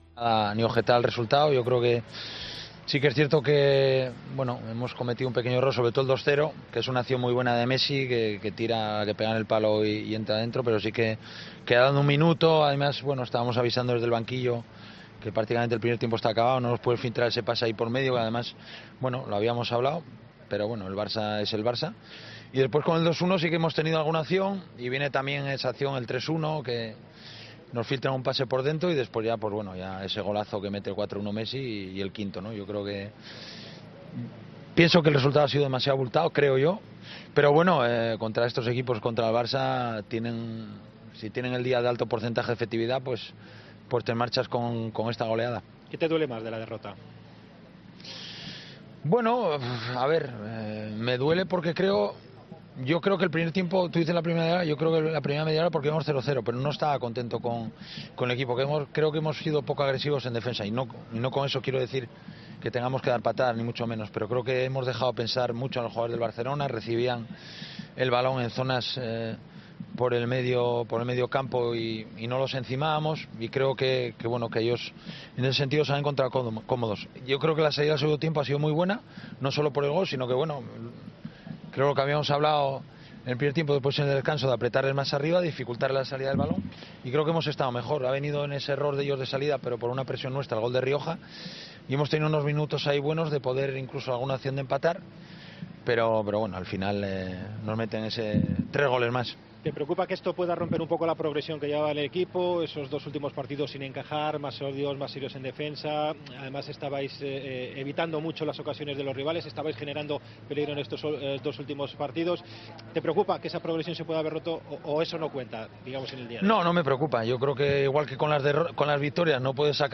Escucha el análisis de Abelardo tras perder en el Camp Nou: "El resultado ha sido demasiado abultado. Hemos dejado mucho pensar a los jugadores del Barça, han jugado cómodo".